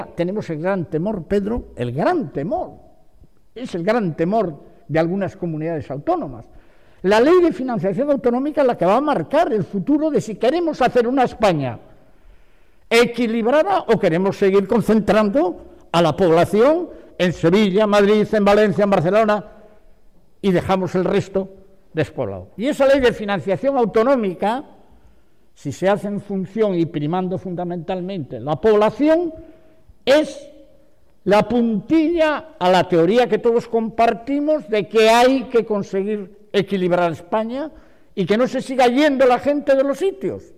“Hay cosas que tendrían que cambiar para que nos sintiéramos todos partícipes de un país sin privilegios para unos y castigos para otros”, señala el presidente cántabro en la apertura del Diálogo para el Futuro del Trabajo.